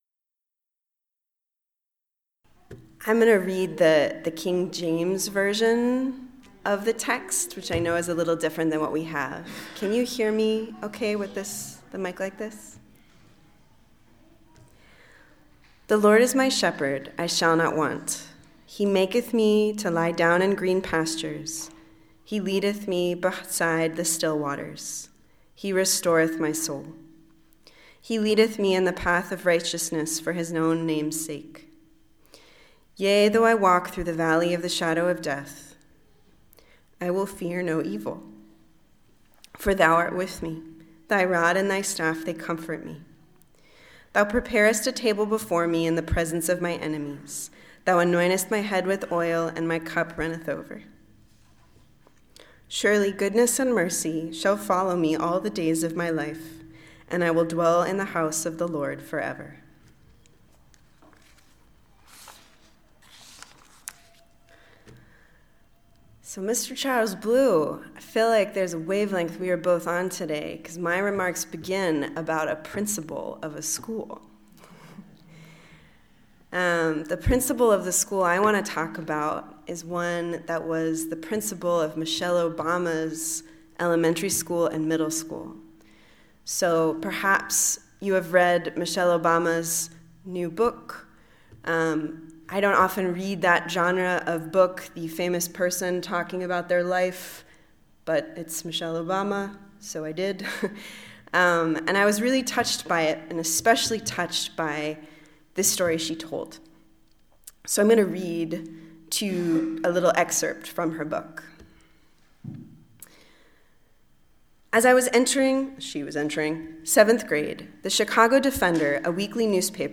Listen to the most recent message, “Peace from Fear,” from Sunday worship at Berkeley Friends Church.